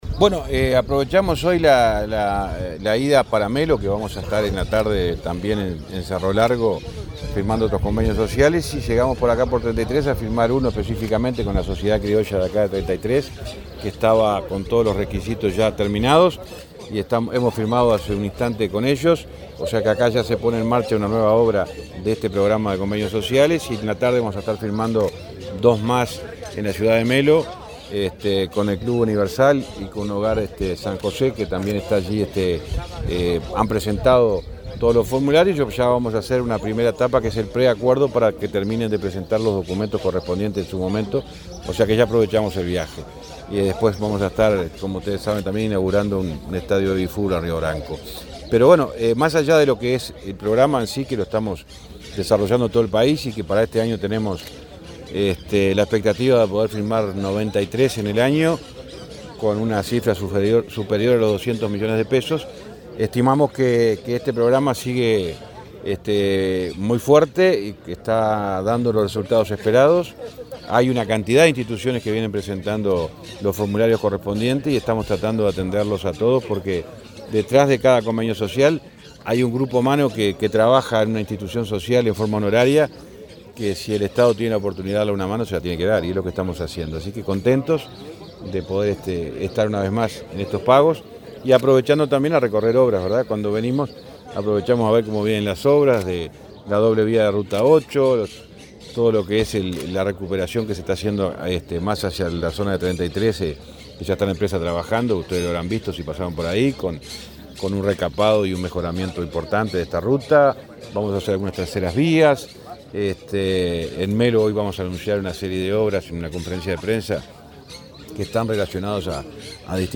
Entrevista al ministro de Transporte, José Luis Falero
Entrevista al ministro de Transporte, José Luis Falero 27/04/2023 Compartir Facebook X Copiar enlace WhatsApp LinkedIn El ministro de Transporte, José Luis Falero, dialogó con Comunicación Presidencial en Treinta y Tres, donde firmó convenios sociales con varias instituciones.